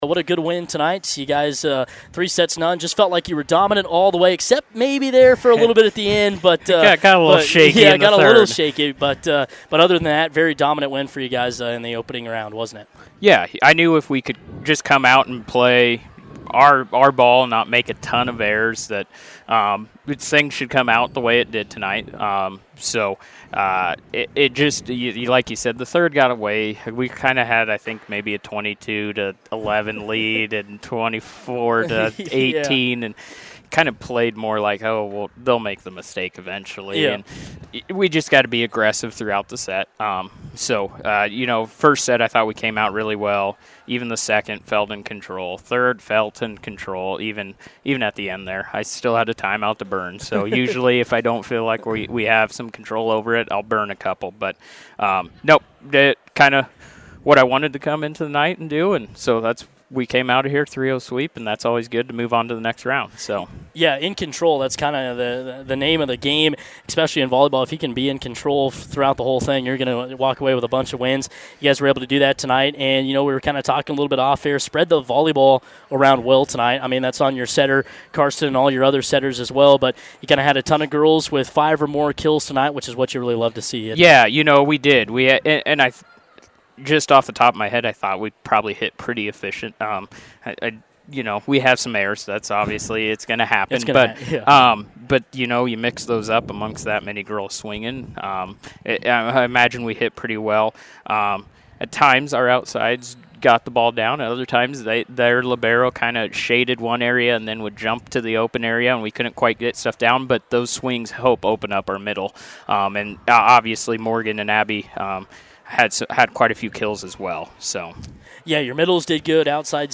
INTERVIEW: Bison volleyball sweeps Lexington in Class B7 subdistrict semifinals, face Grand Island NW for subdistrict title.